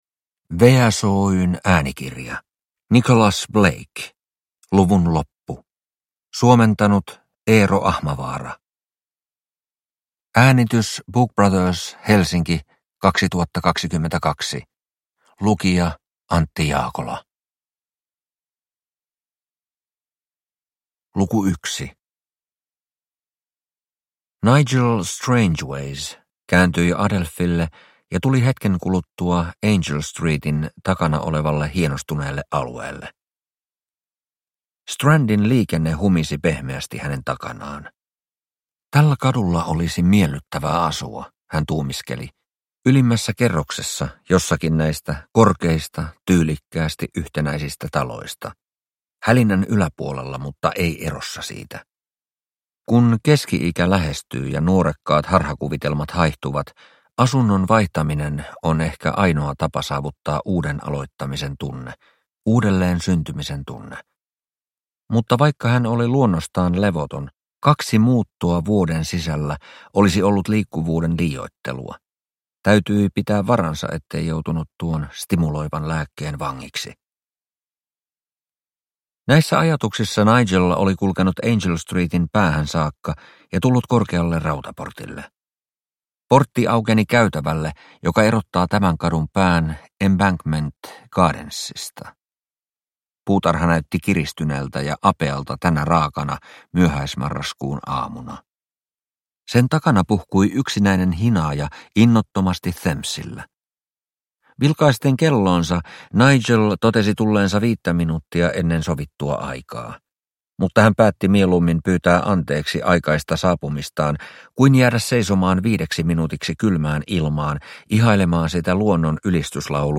Luvun loppu – Ljudbok – Laddas ner